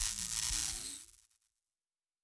pgs/Assets/Audio/Sci-Fi Sounds/Weapons/Weapon 16 Stop (Laser).wav at master
Weapon 16 Stop (Laser).wav